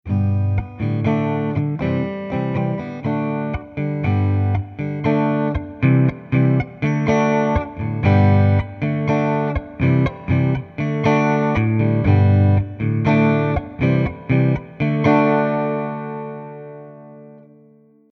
Putting A Basic Acoustic Song Together
G D C D